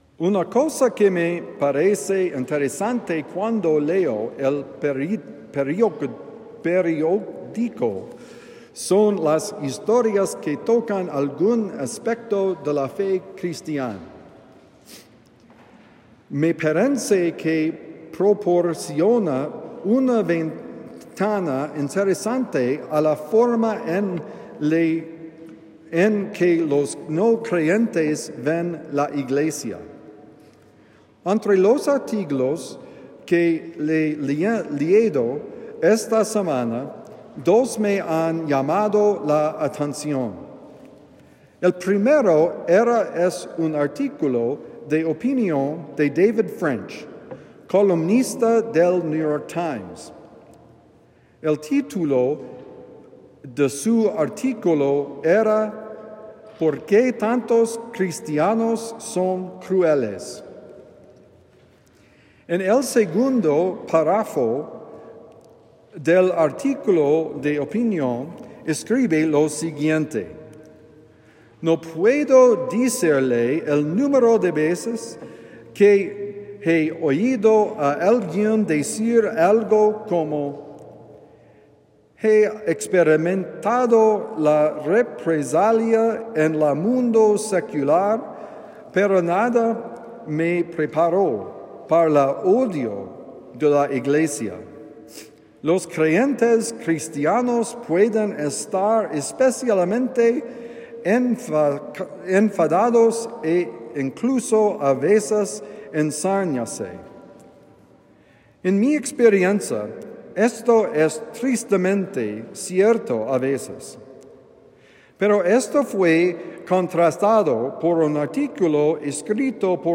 Convertirse en una familia santa: Homilía del domingo 29 de diciembre – The Friar